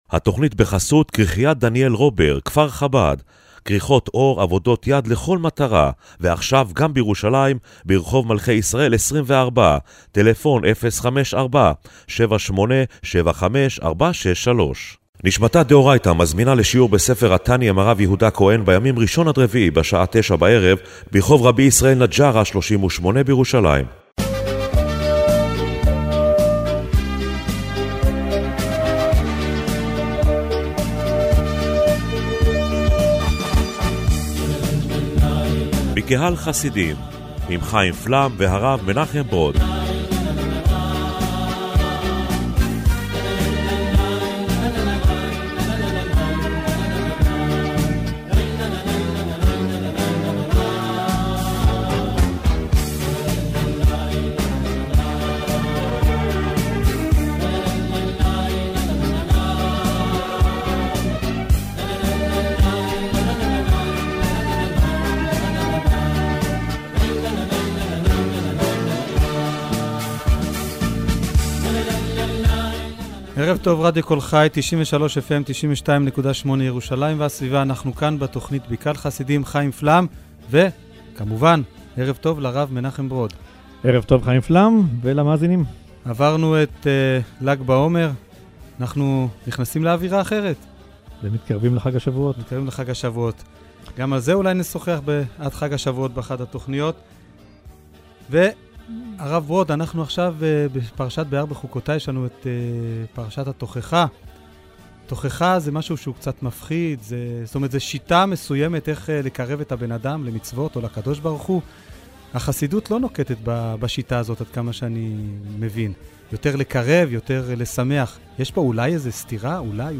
במרכז תכנית הרדיו השבועית בקהל חסידים השבוע עמדה ההתייחסות של החסידות לתוכחה.